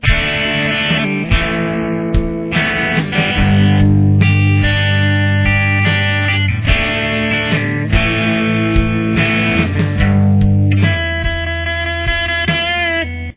Guitar 1